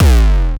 Desecrated bass hit 03.wav